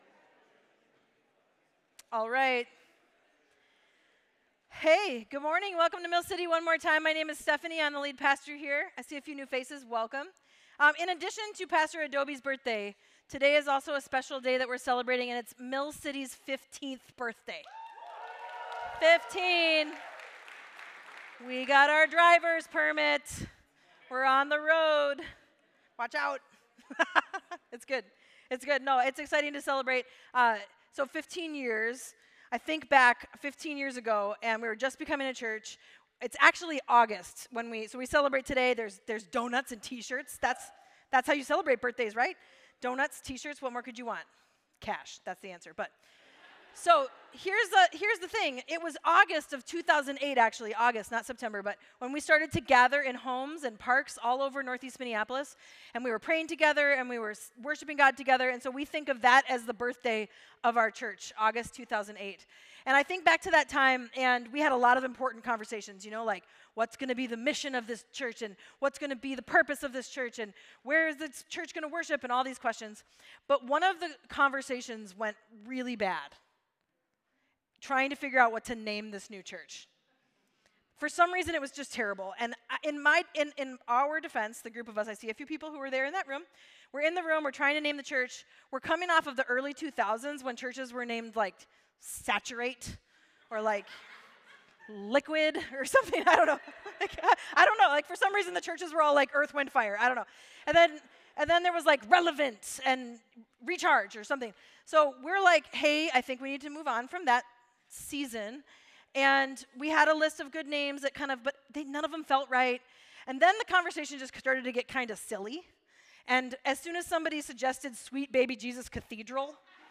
Mill City Church Sermons The Way of Jesus: A Way Through The Wilderness Sep 10 2023 | 00:39:12 1x 00:00 / 00:39:12 Subscribe Share RSS Feed Share Link Embed